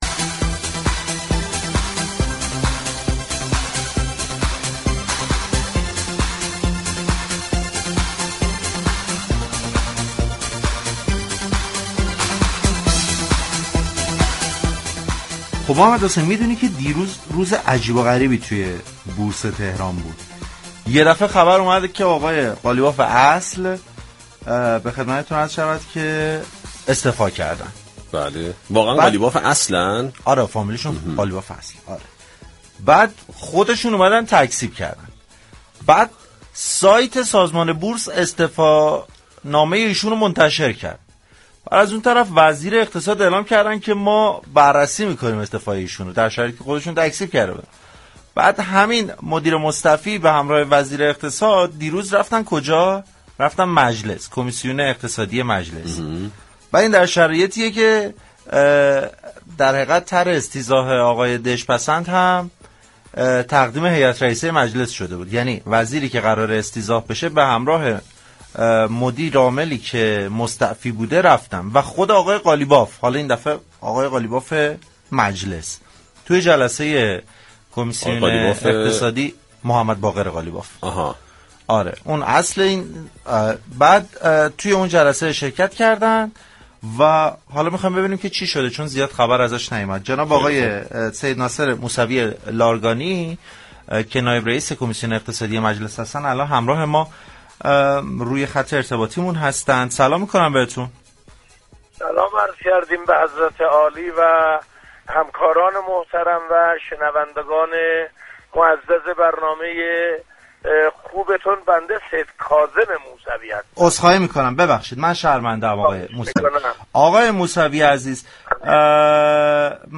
در همین راستا برنامه پارك شهر در گفتگو با سیدكاظم موسوی نایب رئیس كمیسیون اقتصادی مجلس یازدهم، جزئیات جلسه مشترك روز سه شنبه 30 دیماه را جویا شد.